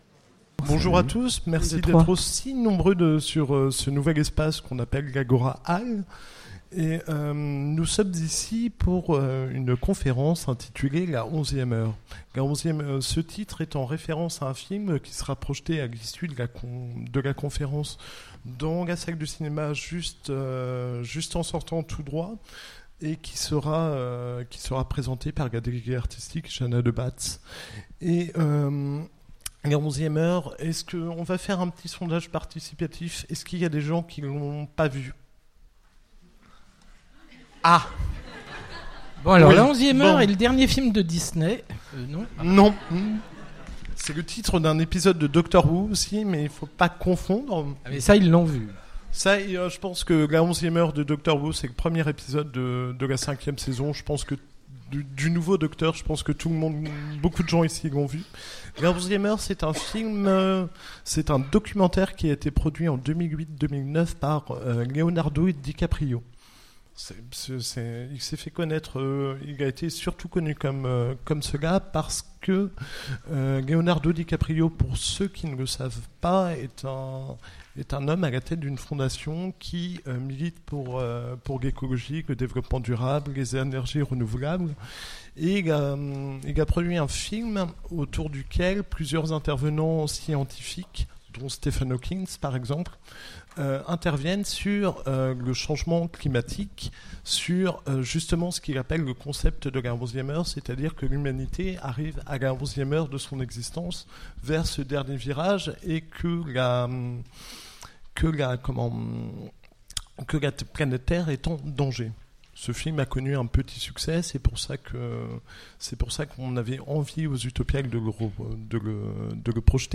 Utopiales 2017 : Conférence La onzième heure